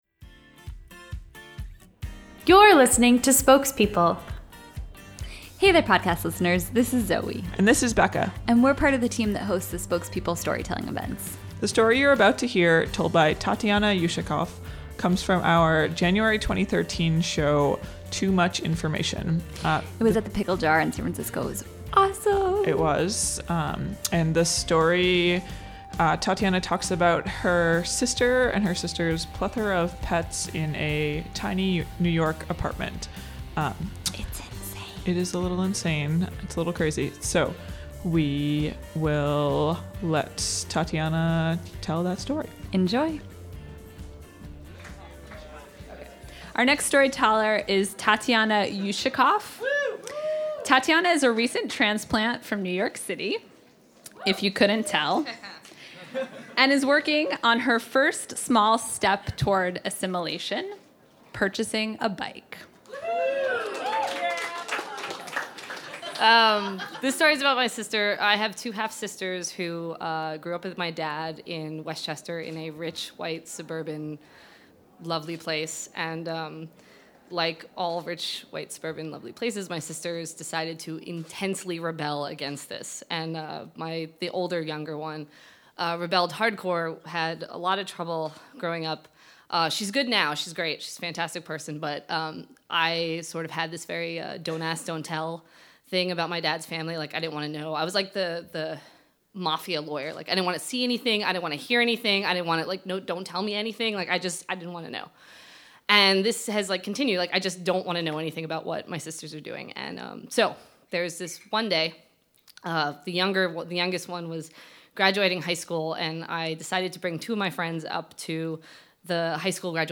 Homepage / Podcast / Storytelling
This story of reptilian family relations comes from our January 2013 show, Too Much Information.